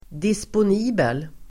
Uttal: [dispon'i:bel]